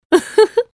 Download Nico Robin Laugh sound button
nico-robin-laugh.mp3